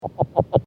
Sound  This is a recording of the advertisement calls of a Northern Red-legged Frog recorded underwater in Humboldt County.